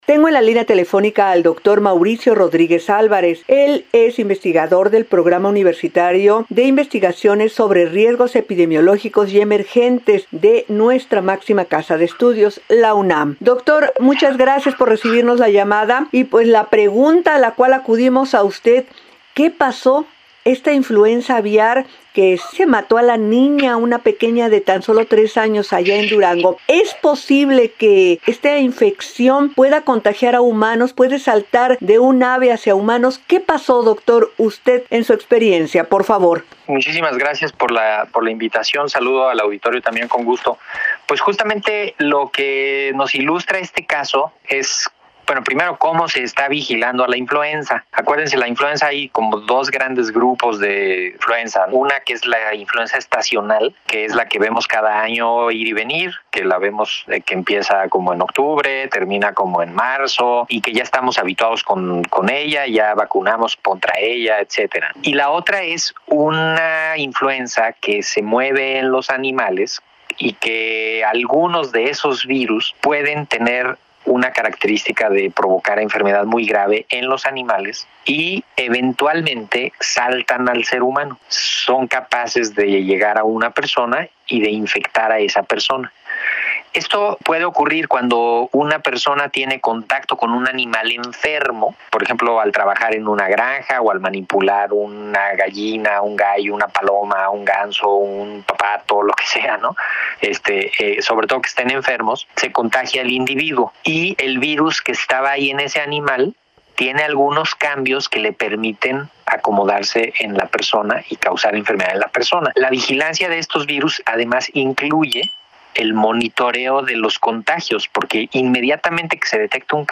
19-ENTREVISTA-GRIPE-AVIAR.mp3